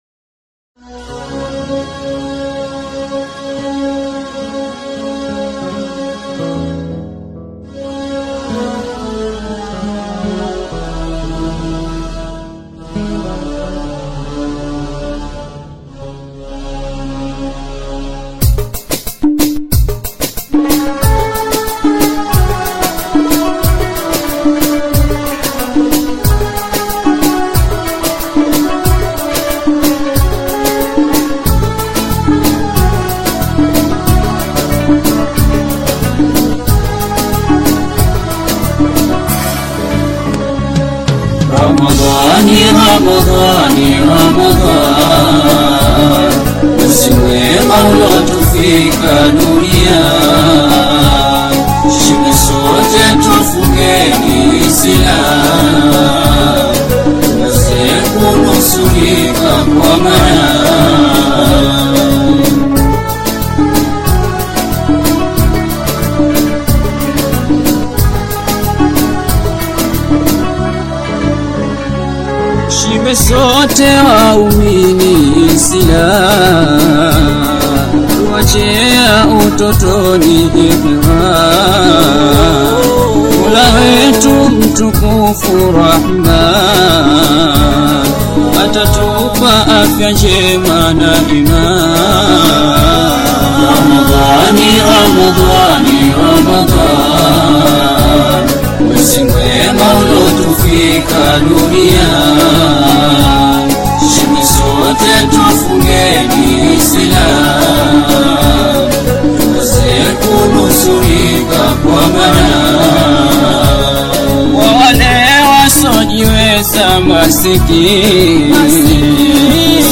AudioQaswida
Afro-sounds single
rich cultural rhythm and vocal depth